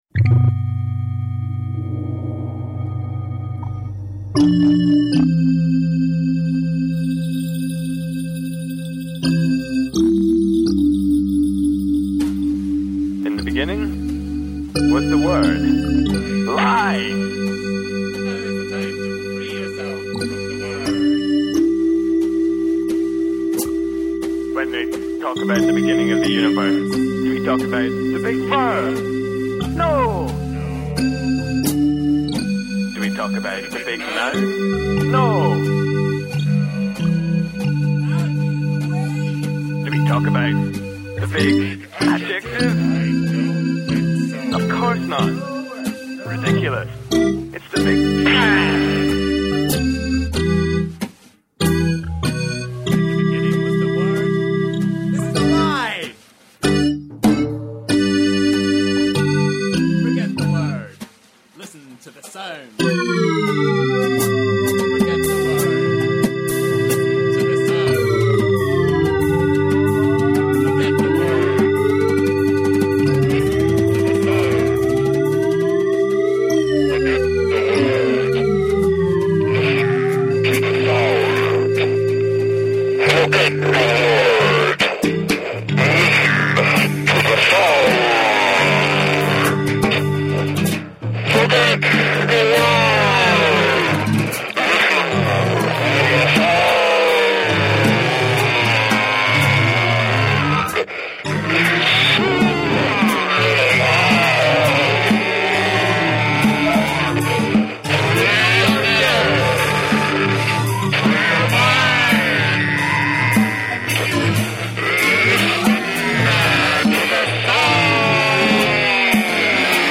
sonology @ resonance fm (london, 2002): samples from the Amplification as part of “You Are Hear” broadcast and netcast on Resonance FM on May 13th 2002.
sonology @ resonance fm (london, 2002)
sermon.mp3